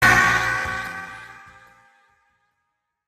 goku-screaming_GhcU2D8.mp3